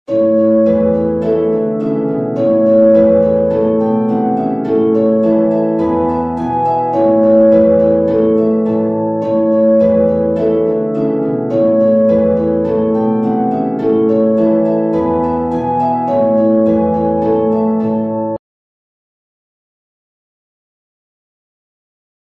LEVER HARP or PEDAL HARP